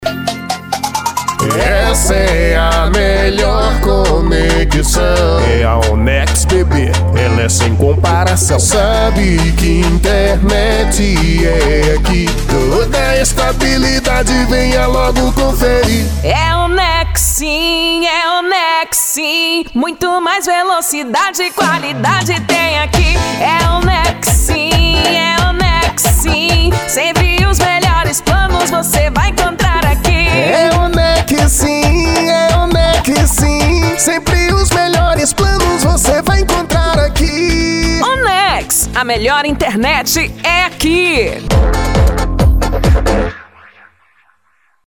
Jingle Comercial Para Provedor de Internet